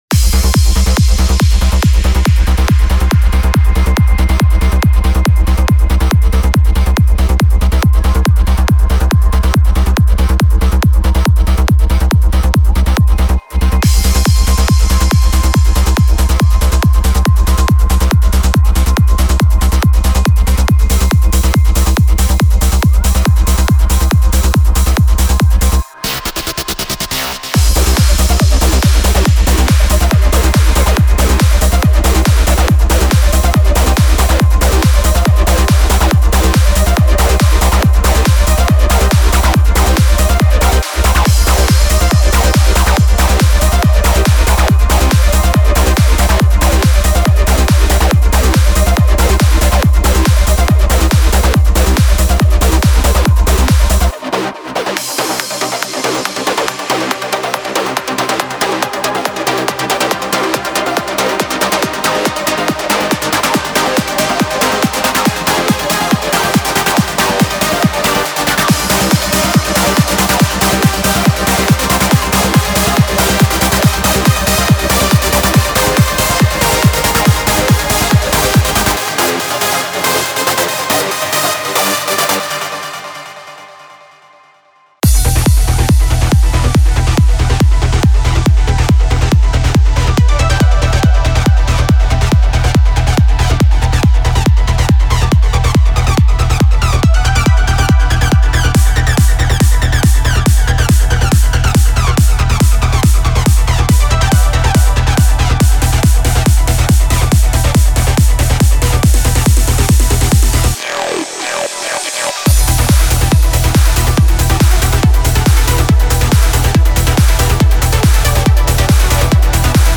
Mixdown Of Demo.